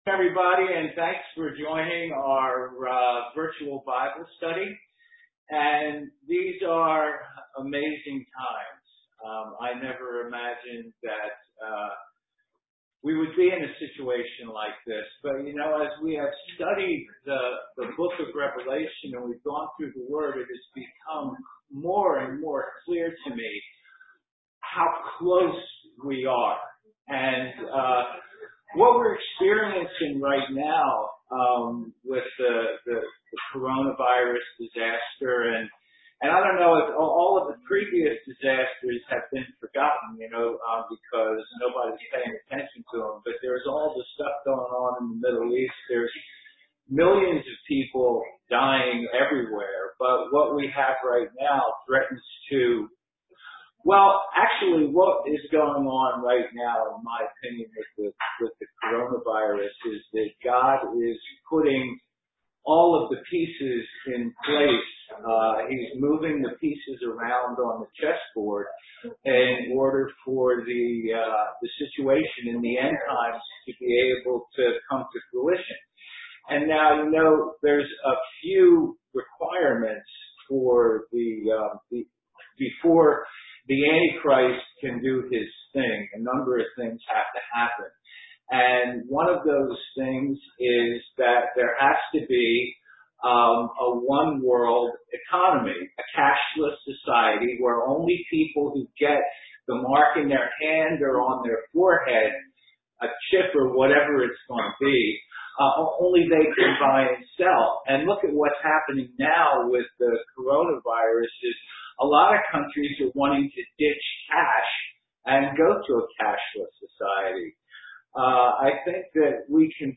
So the audio in the zoom meeting was pretty poor, but the recording came out well.